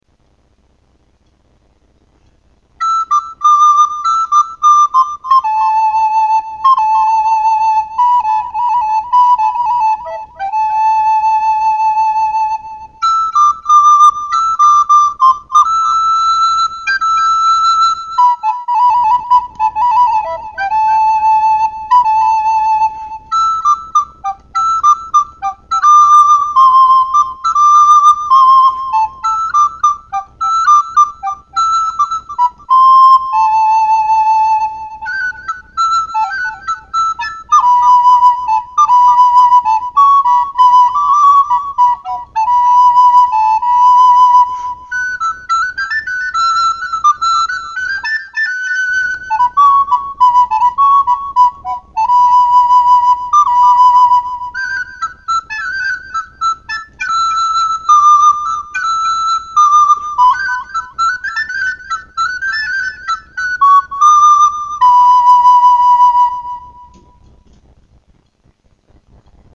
The Butterfly You Painted Has Flown Away (Acoma Pueblo), soprano recorder